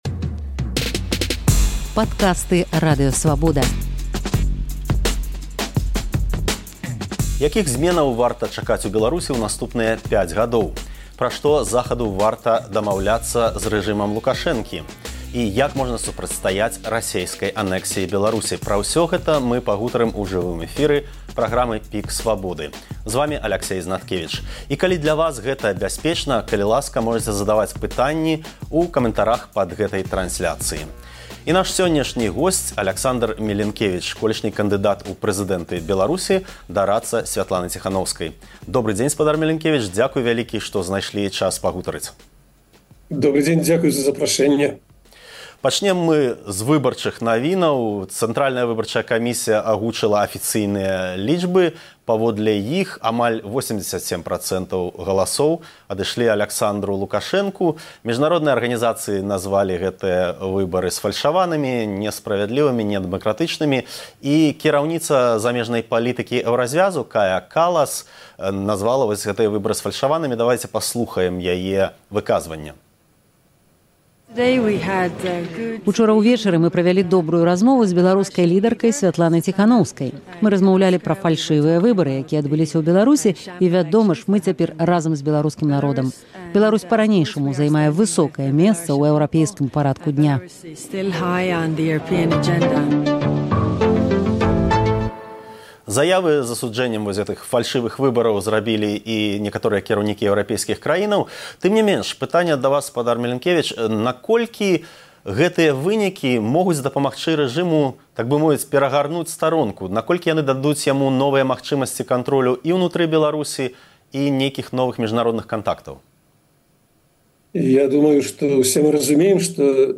Якіх зьменаў можна чакаць у Беларусі ў наступныя 5 гадоў? Пра што Захаду варта весьці перамовы з рэжымам Лукашэнкі? Як можна супрацьстаяць расейскай анэксіі Беларусі? У праграме «ПіК Свабоды» разважае Аляксандар Мілінкевіч, экс-кандыдат у прэзыдэнты Беларусі, дарадца Сьвятланы Ціханоўскай.